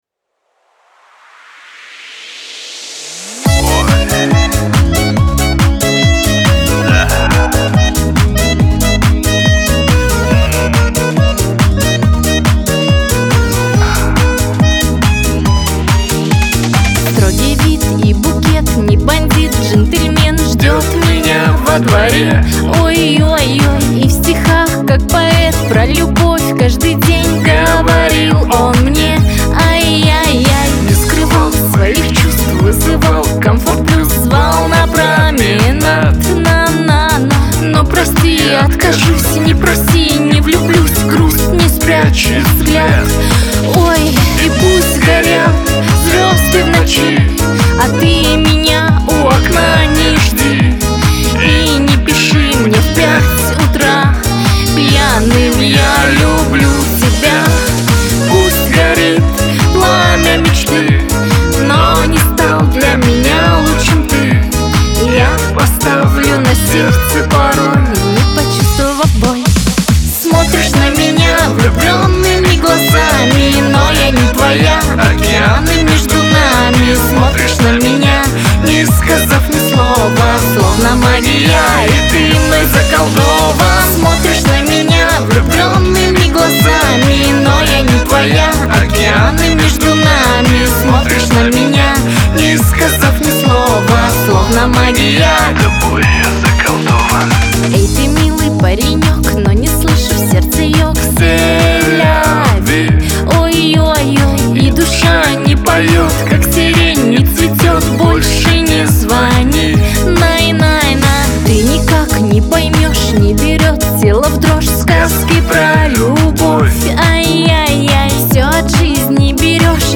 Лирика
грусть